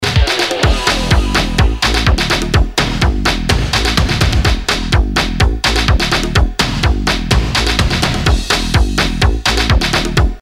Bass.mp3